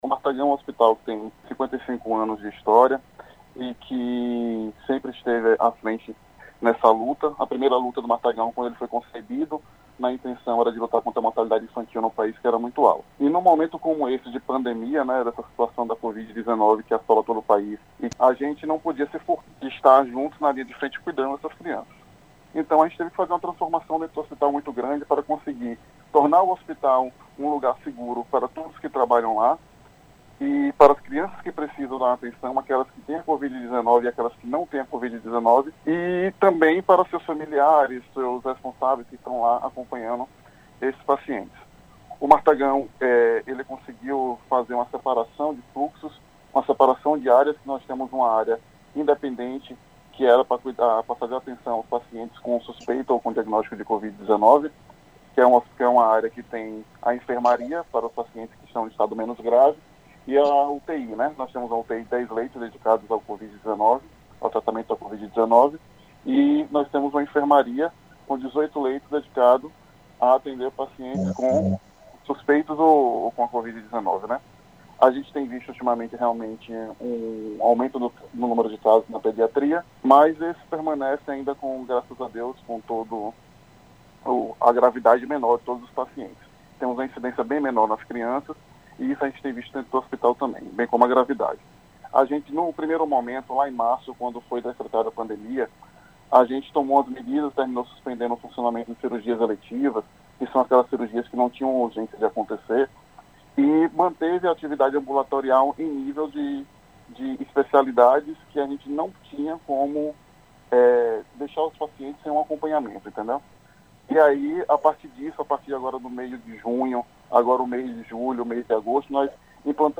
O Excelsior Saúde, acontece das 9 às 10h com transmissão pela Rádio Excelsior AM 840.